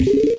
Vine - Super Mario Advance 4: Super Mario Bros. 3